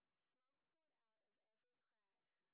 sp13_street_snr30.wav